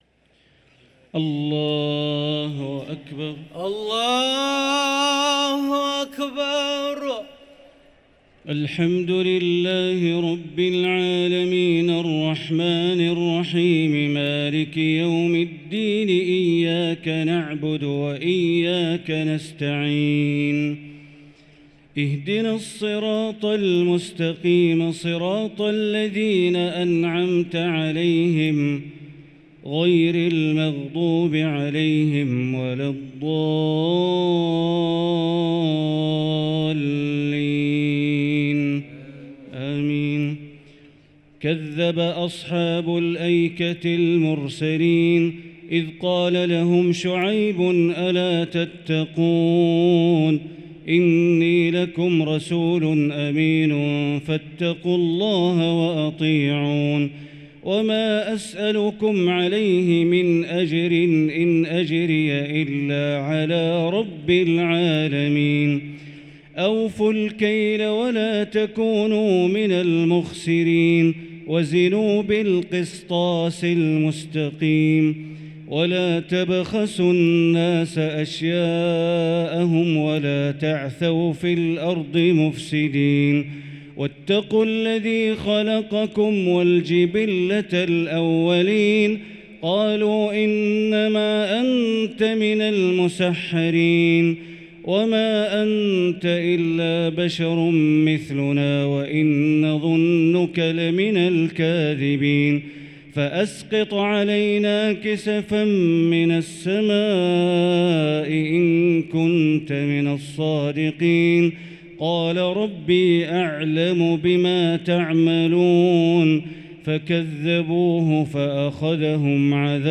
صلاة التراويح ليلة 23 رمضان 1444 للقارئ بندر بليلة - التسليمتان الأخيرتان صلاة التراويح